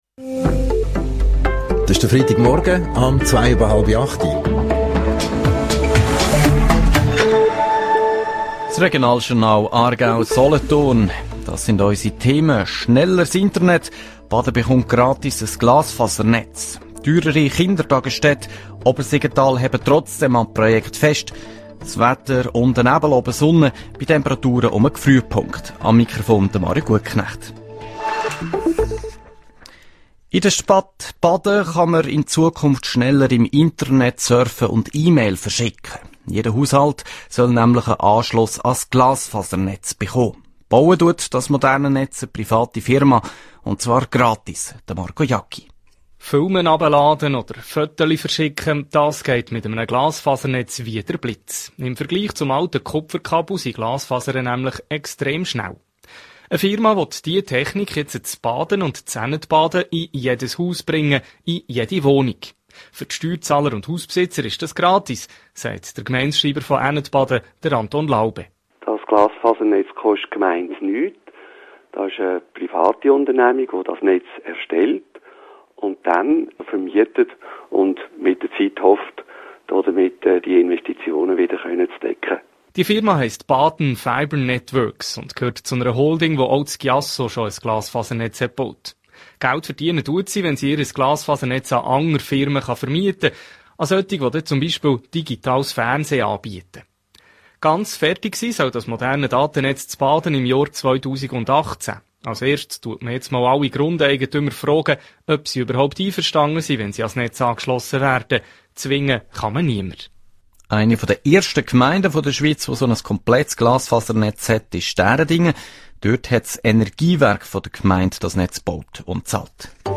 Radio-Beitrag SRF